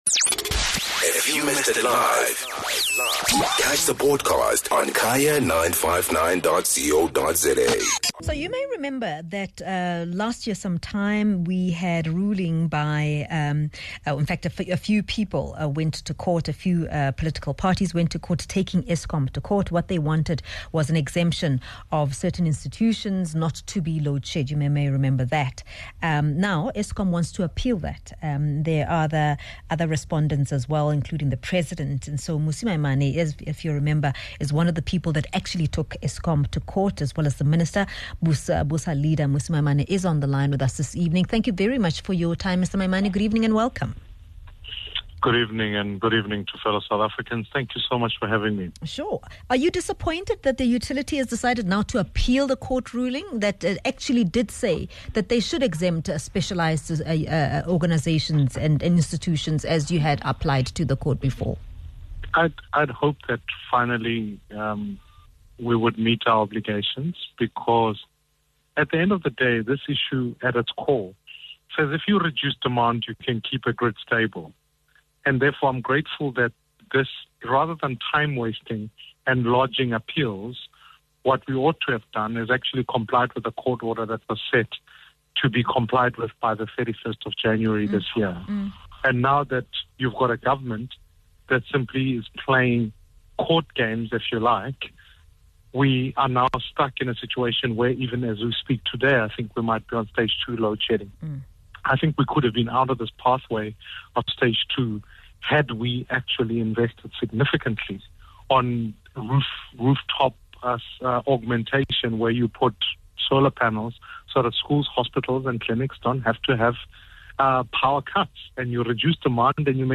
Leader of the Build One SA (Bosa) party, Maimane joins us on the line.